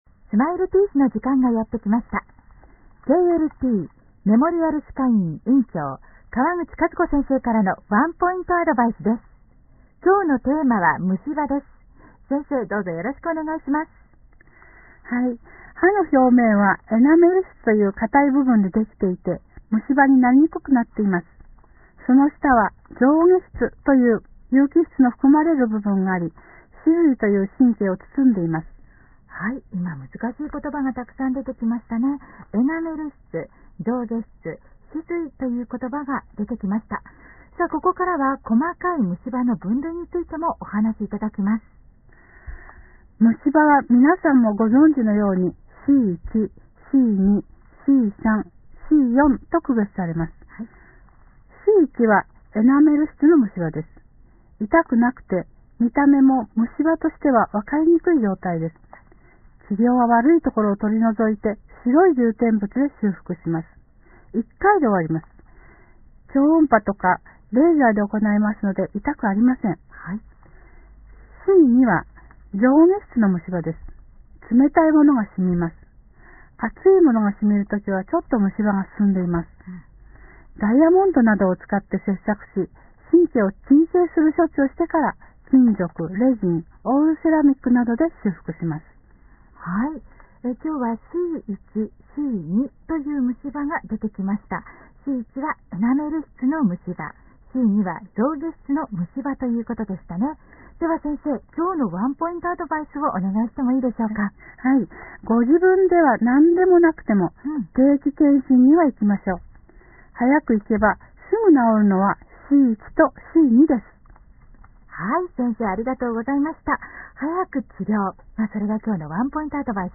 院長のラジオ番組出演収録話